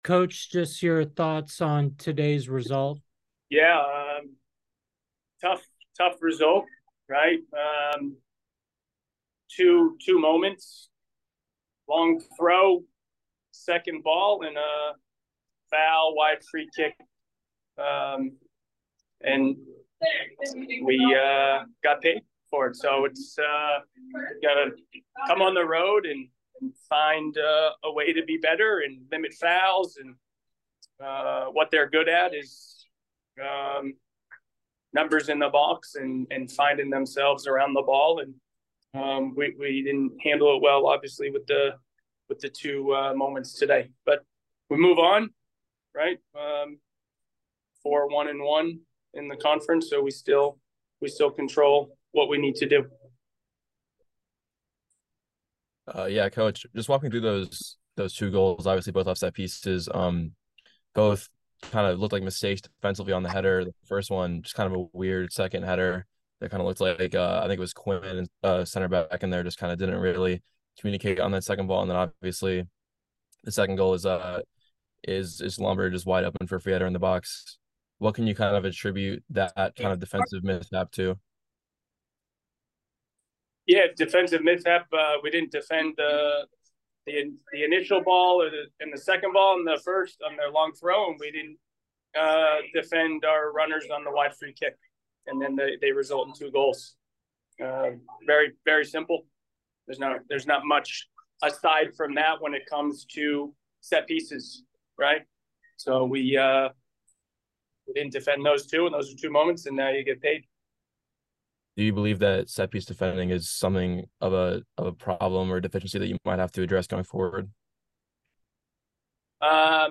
Lafayette Postgame Interview